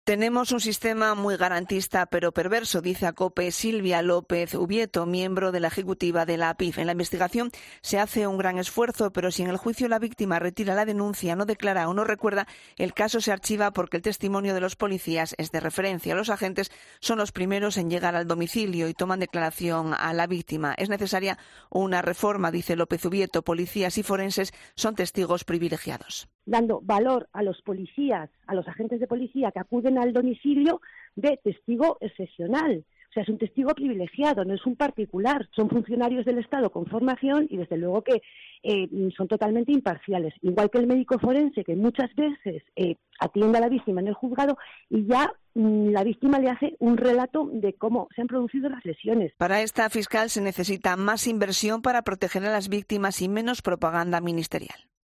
crónica